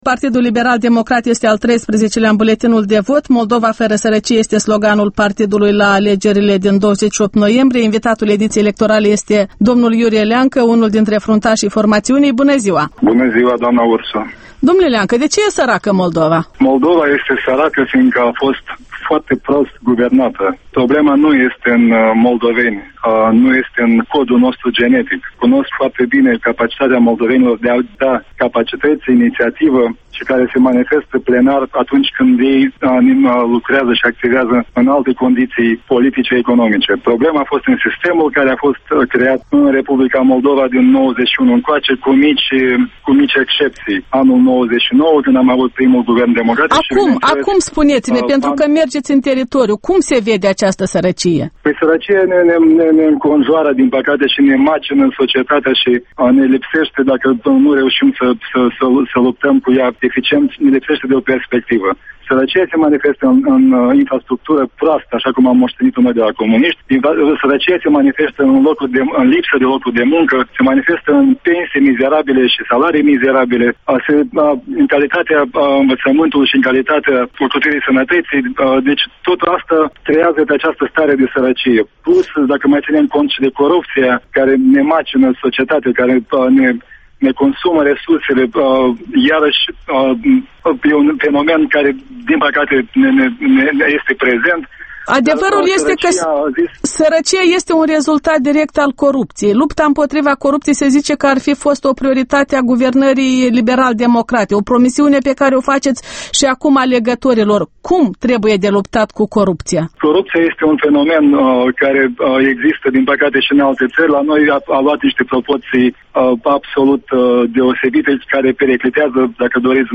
Interviul Electorala 2010: cu Iurie Leancă